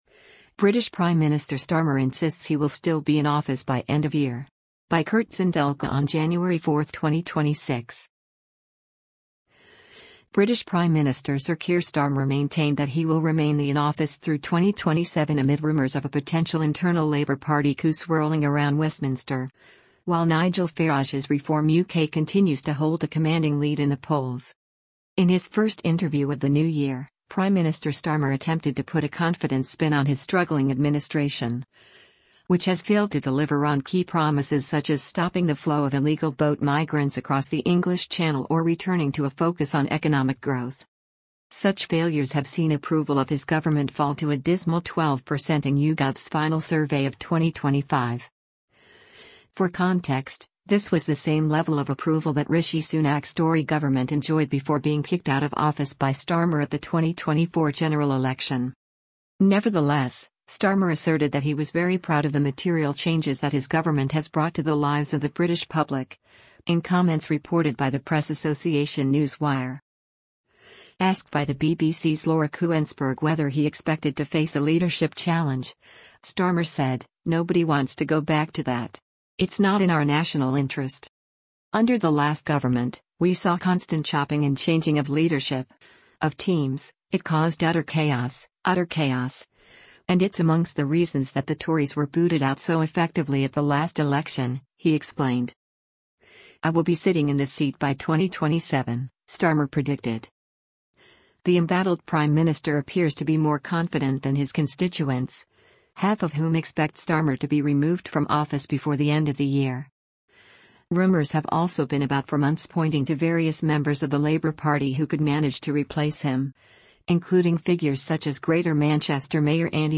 Prime Minister Keir Starmer is interviewed by BBC's La